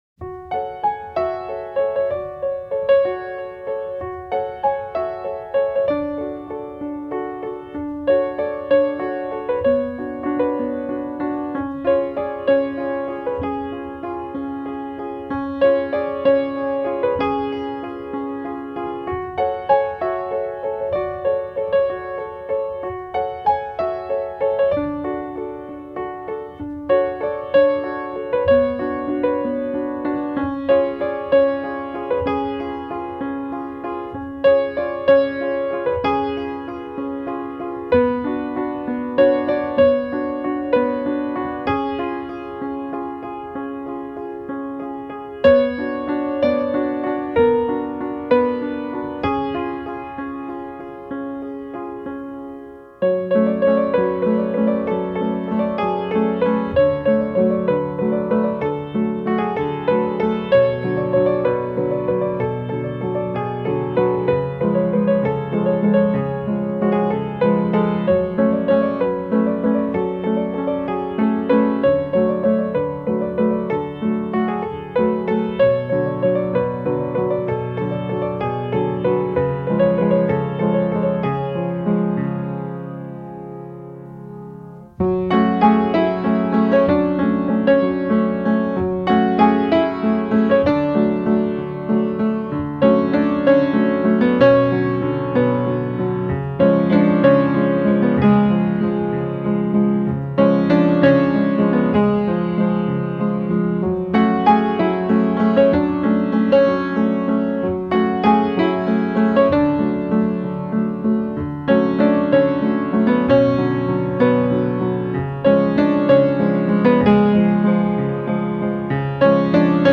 Valse.mp3